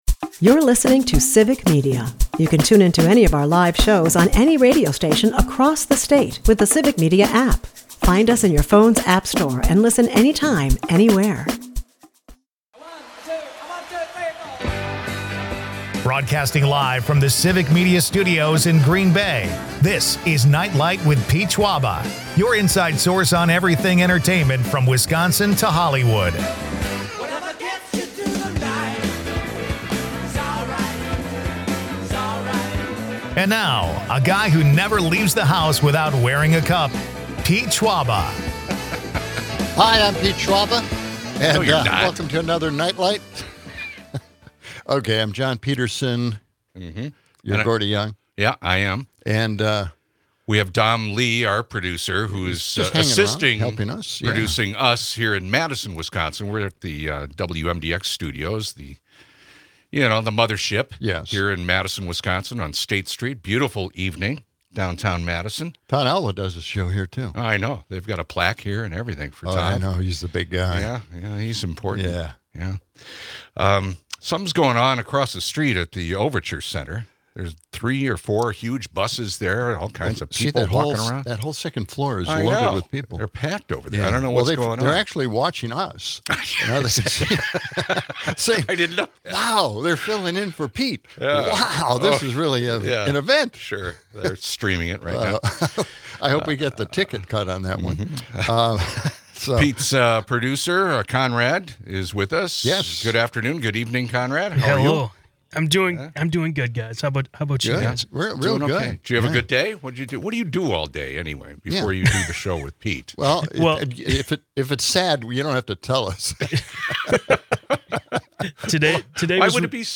Meanwhile, listeners weigh in on their own supernatural experiences.
The episode takes a lighter turn with breaking celebrity news: Taylor Swift and Travis Kelsey's engagement, which reportedly shattered online records. Amid ghostly apparitions and UFO sightings, the hosts keep things lively with banter and listener interactions, ensuring a night of thrills and laughs.